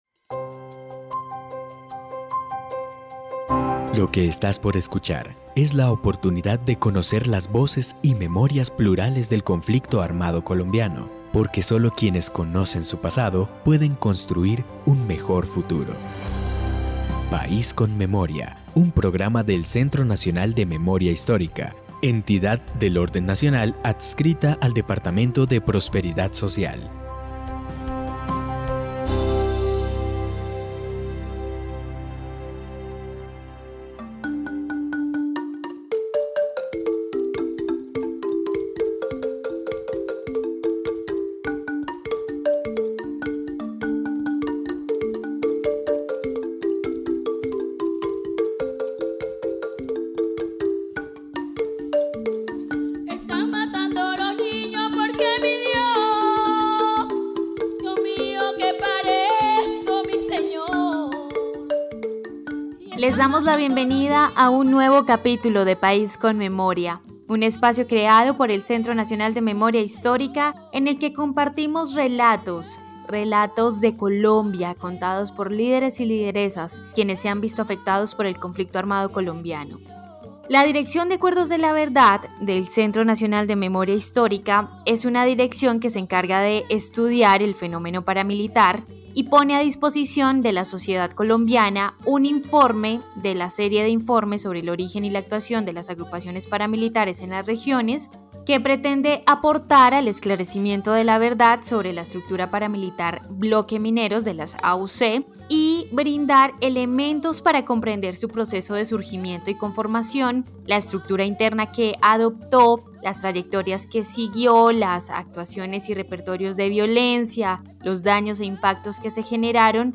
locución.